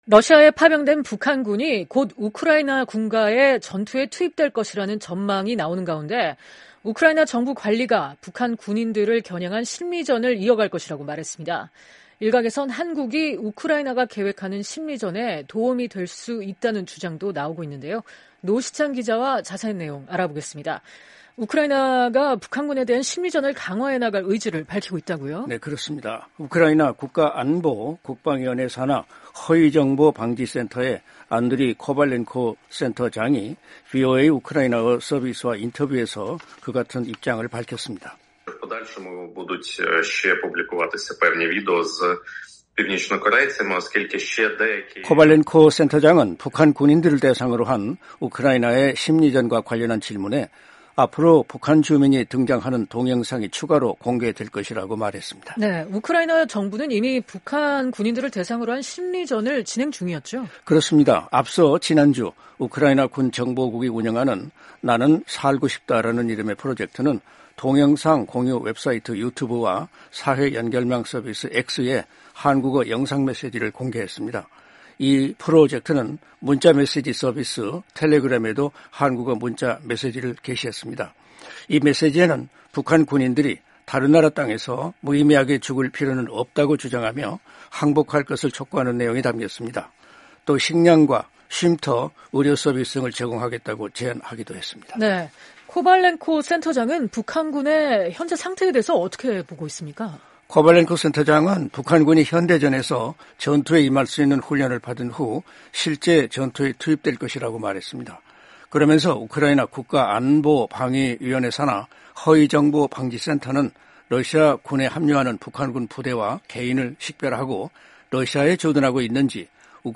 기자와 자세한 내용 알아보겠습니다.